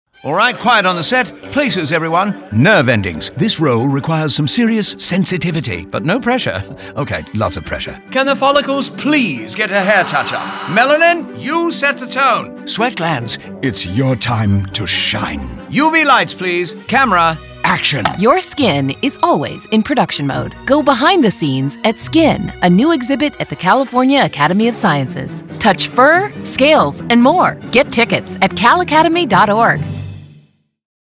Our integrated campaign for the California Academy of Sciences celebrates “nature’s secret language” through vibrant hidden imagery in TV, online video, digital and print. In radio, we let colorful creatures playfully describe their bioluminescence and bring Color of Life, to life.
RADIO